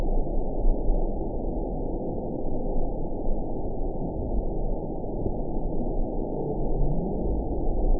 event 922561 date 01/29/25 time 22:08:57 GMT (10 months ago) score 8.47 location TSS-AB10 detected by nrw target species NRW annotations +NRW Spectrogram: Frequency (kHz) vs. Time (s) audio not available .wav